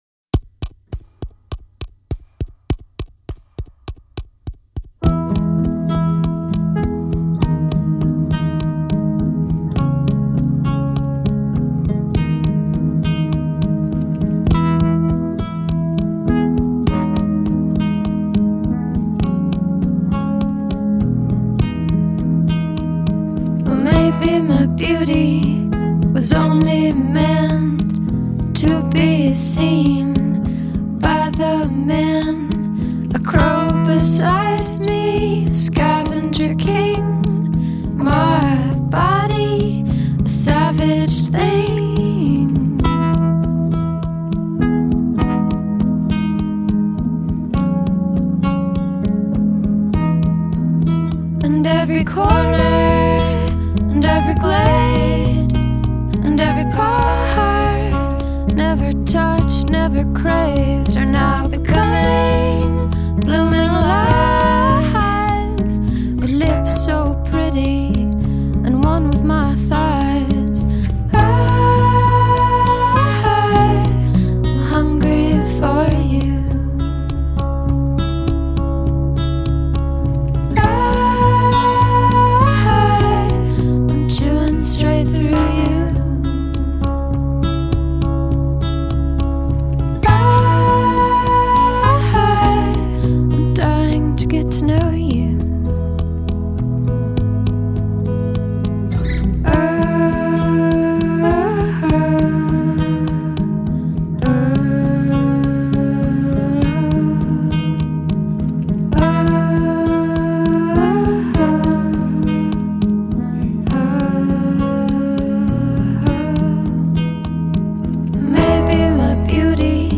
improvisation-fueled musical project